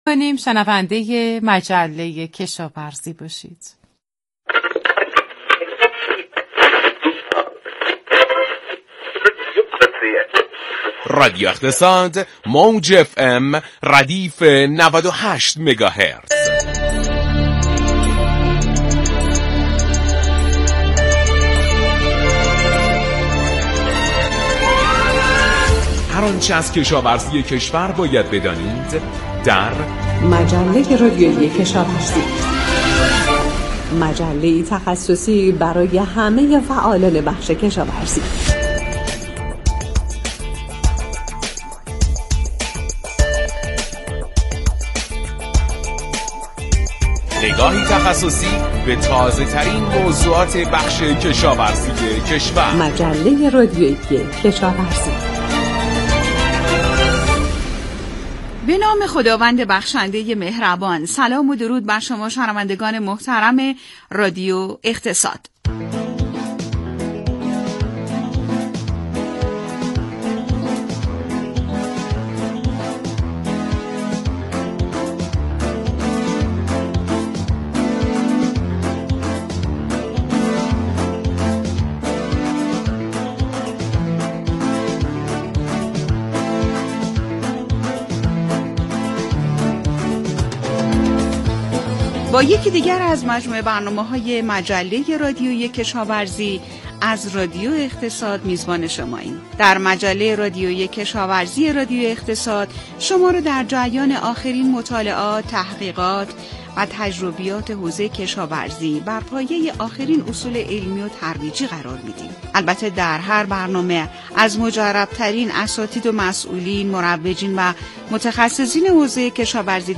مشروح برنامه مجله رادیویی کشاورزی با موضوع: